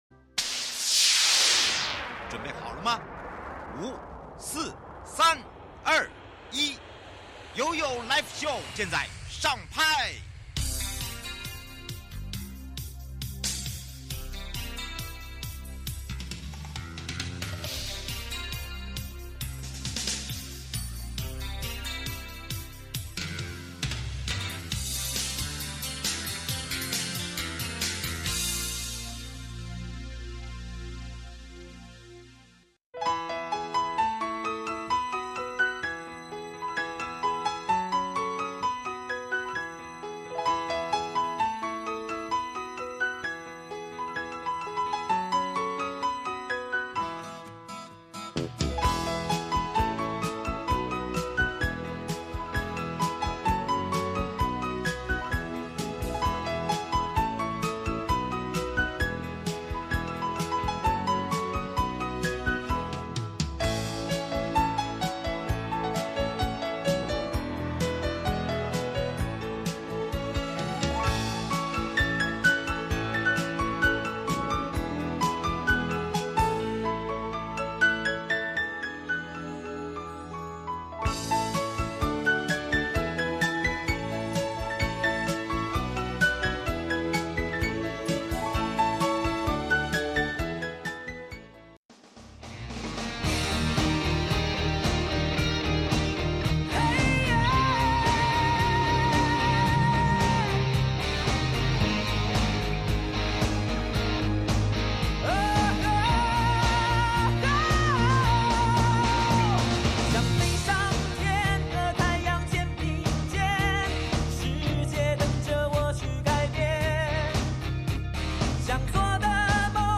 受訪者： 臺灣高等檢察署王啟旭檢察官 節目內容： 常見詐騙手法分享 一、各種網路及投資詐騙手法與預防 (一)港